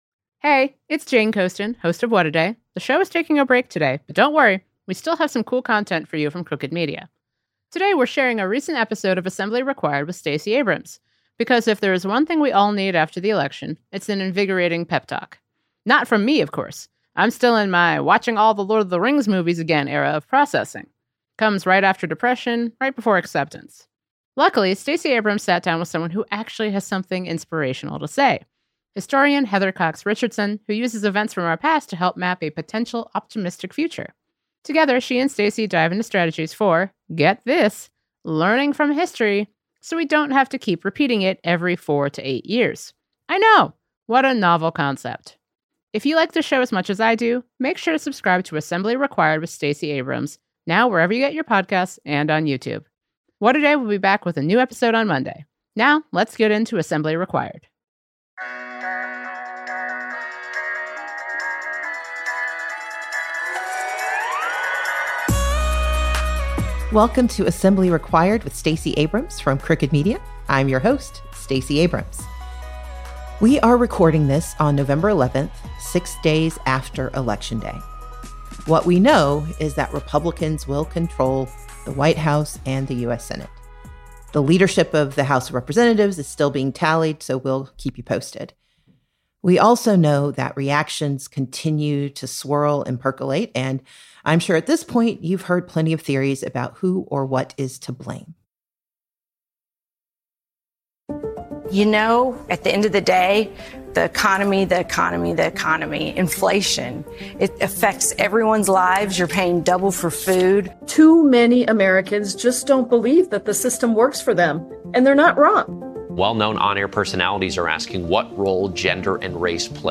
In this episode, Stacey speaks with historian Heather Cox Richardson to see what history can teach us about moving forward after Trump’s reelection. They discuss strategies for countering disinformation, how Democratic leaders are preparing to use states’ rights to their advantage to challenge Trump’s federal overreach, and how the era following William McKinley’s presidency can be a guide for progressives. Then, Stacey answers questions from the audience on how to get involved